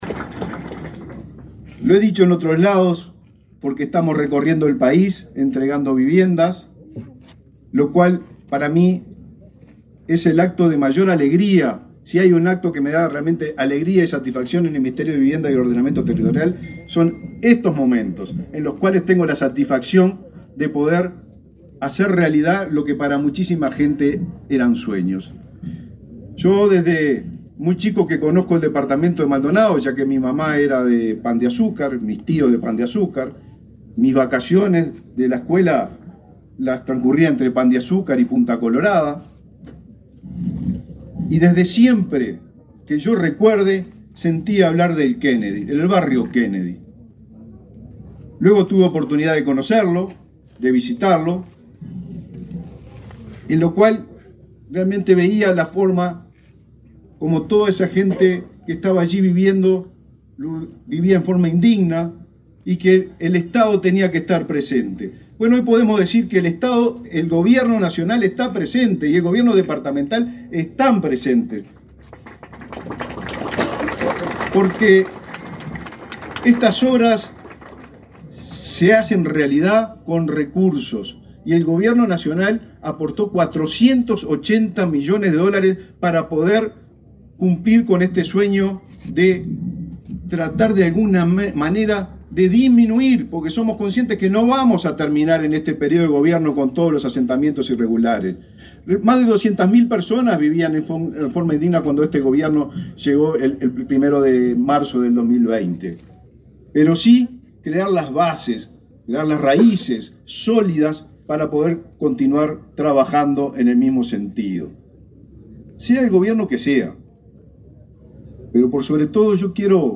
Palabras del ministro de Vivienda, Raúl Lozano
El ministro de Vivienda, Raúl Lozano, participó, este miércoles 21 en Maldonado, en el acto de inauguración de viviendas, construidas para el realojo